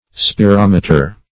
Spirometer \Spi*rom"e*ter\, n. [L. spirare to breathe + -meter.]